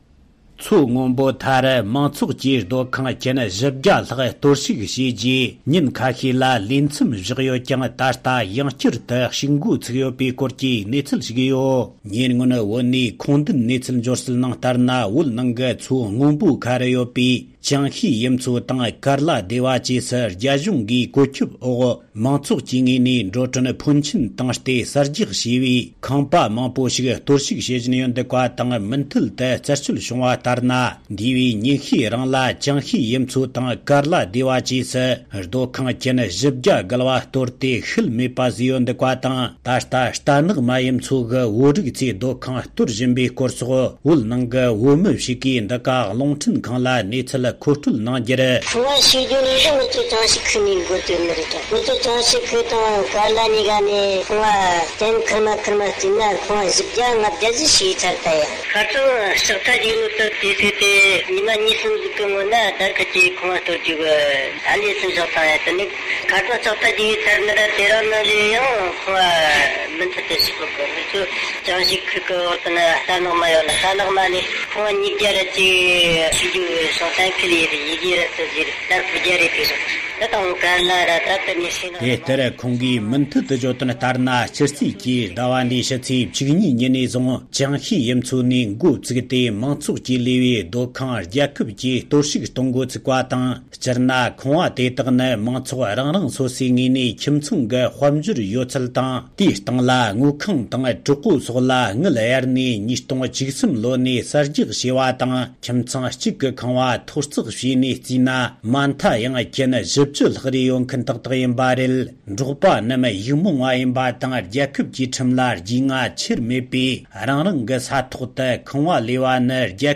ད་ལྟ་རྟ་ནག་མ་ཡུལ་མཚོའི་བོད་མིའི་སྡོད་ཁང་གཏོར་བཞིན་པའི་སྐོར་སོགས་བོད་ནང་གི་བོད་མི་ཞིག་གིས་འདི་ག་རླུང་འཕྲིན་ཁང་དུ་འགྲེལ་བརྗོད་གནང་ཡོད་པ་རེད།